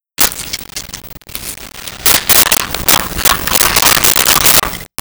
Can Open
Can Open.wav